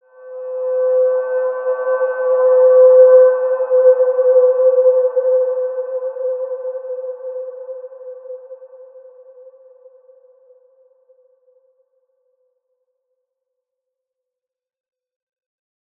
Large-Space-C5-p.wav